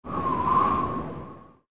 SZ_TB_wind_1.ogg